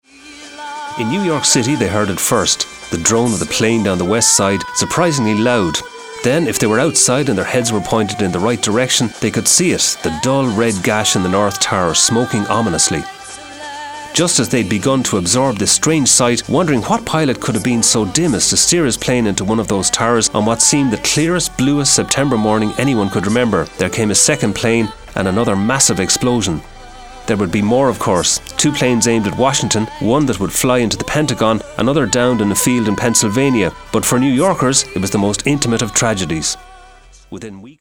"9 / 11 Stories" a WLR documentary, airs on September 7th at 6pm, 20 years on from the terrorist attacks.